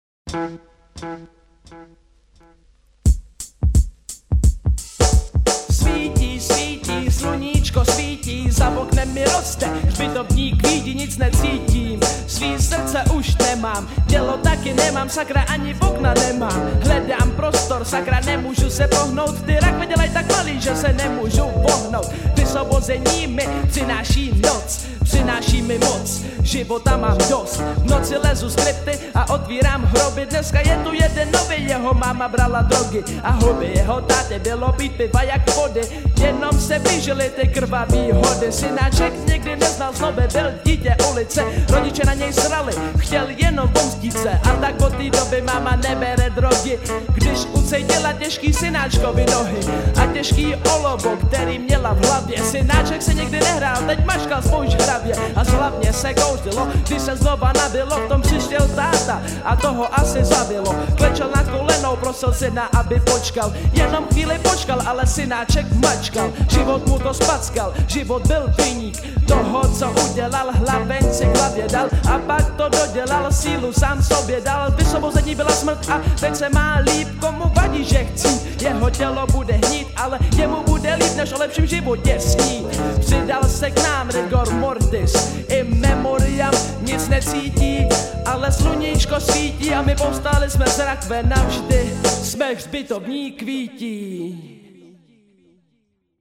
9 Styl: Hip-Hop Rok